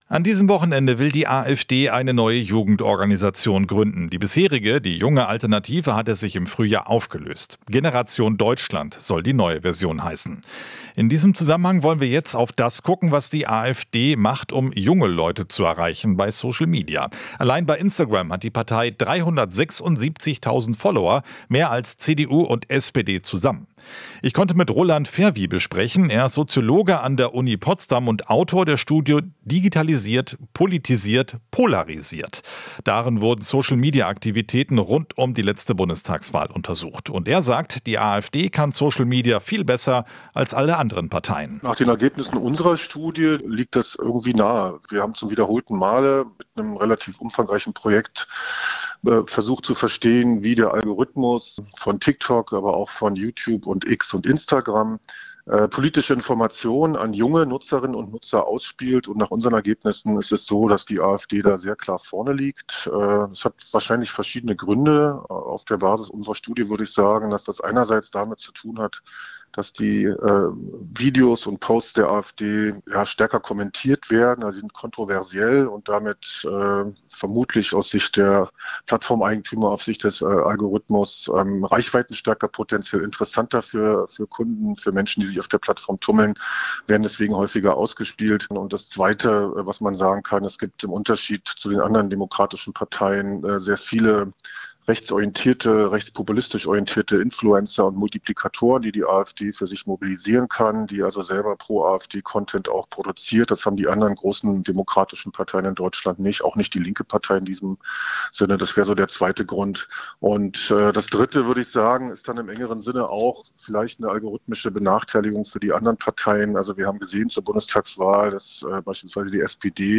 Soziologe: Die AfD kann Social Media viel besser als andere Parteien